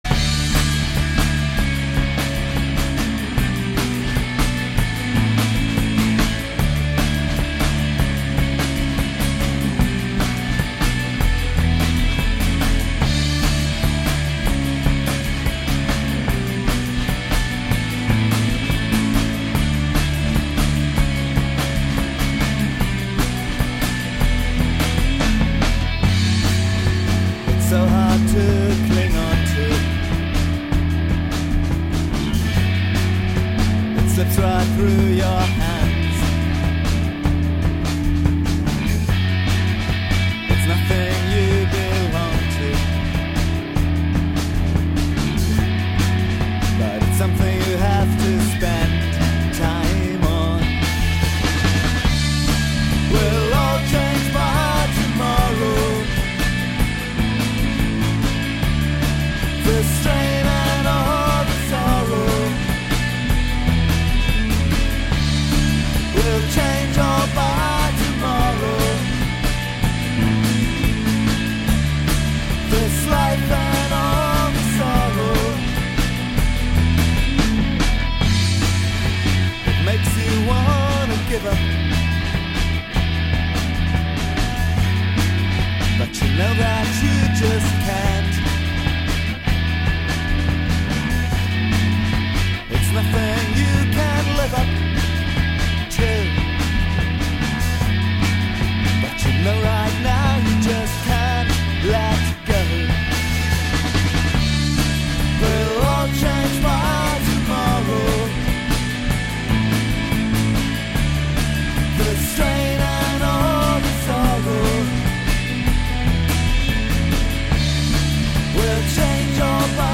The first album from homerecording sessions 2005/06